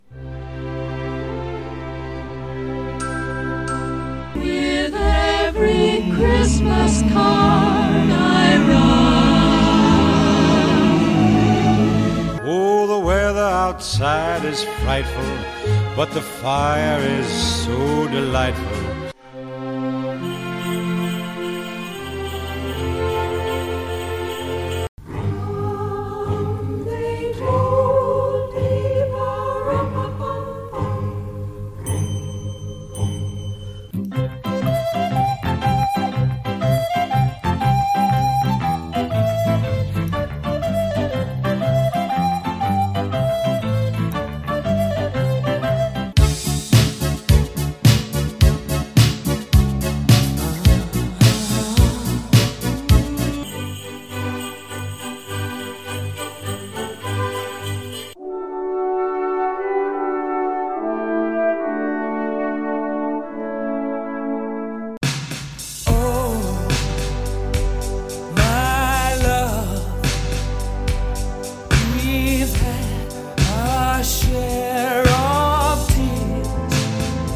Einige charakteristische Takte aus 10 verschiedenen Weihnachtsliedern weisen Euch den Weg zur Dose. Es wird jeweils der Titel gesucht.